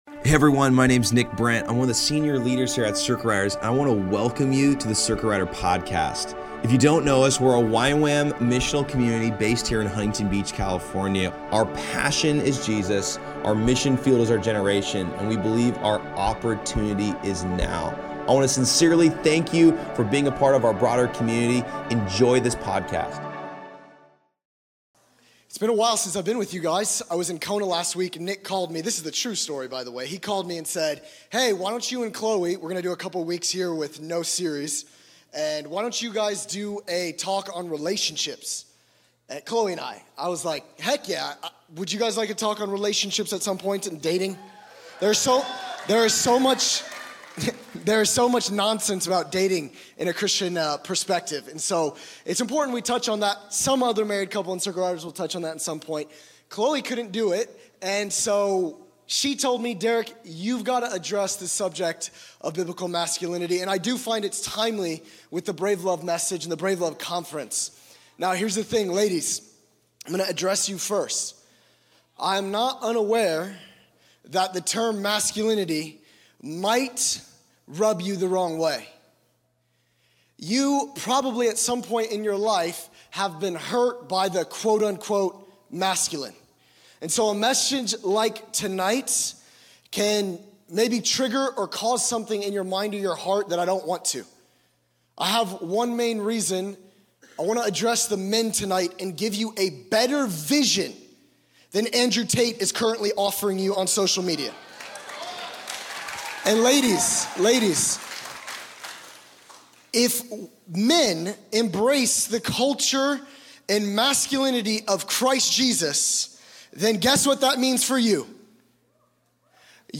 Location Costa Mesa